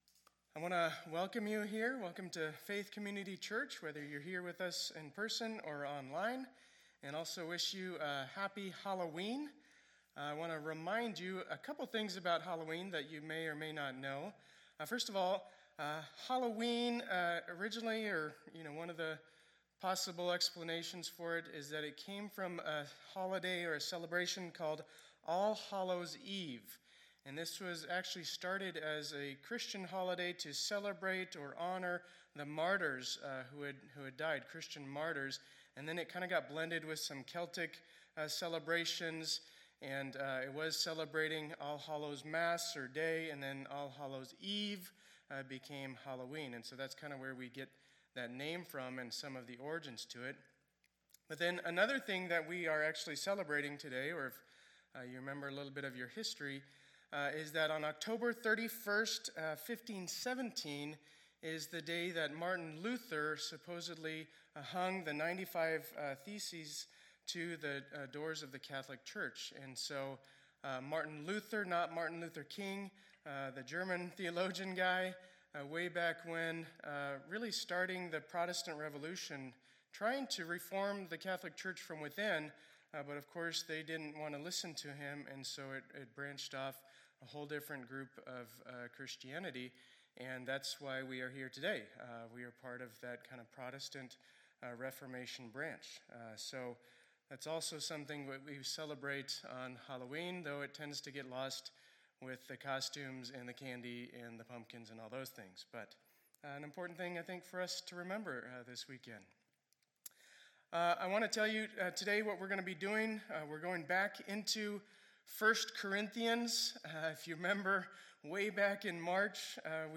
2020-11-01 Sunday Service
Intro, Teaching, Communion, and Lord’s Prayer